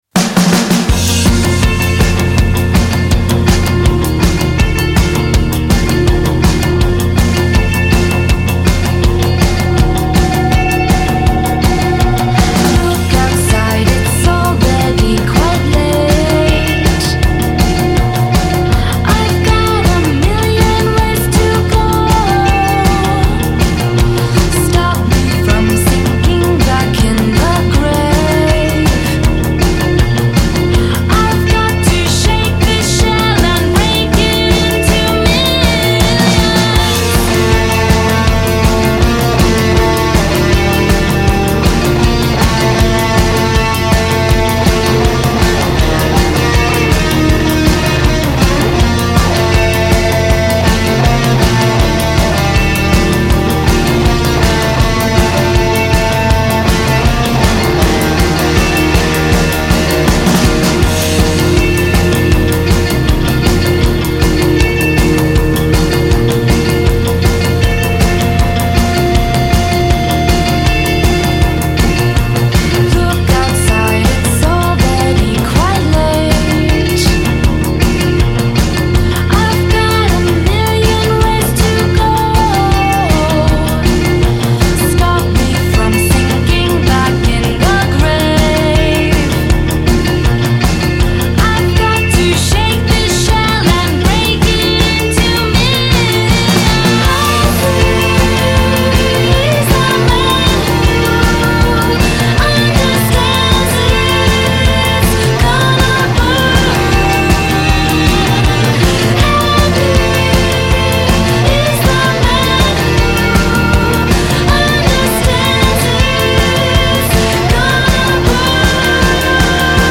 il duo della Virginia
batteria
chitarra e voce
basso